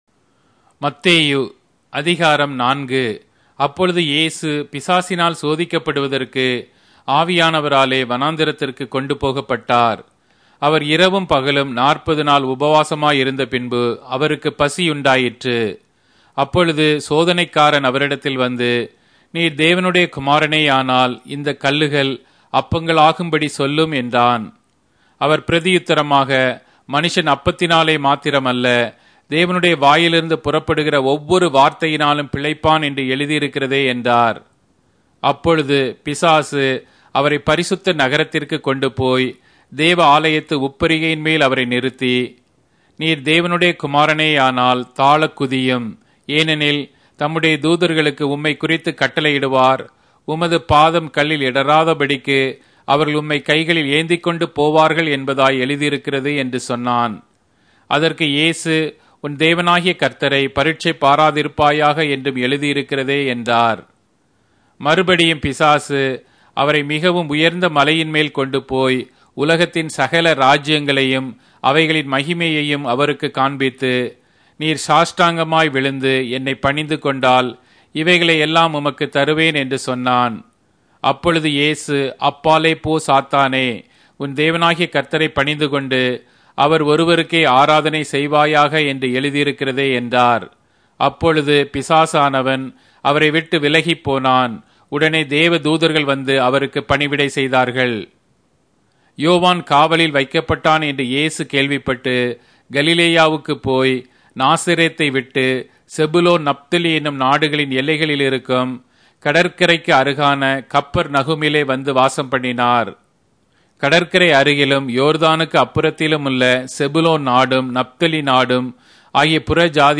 Tamil Audio Bible - Matthew 23 in Akjv bible version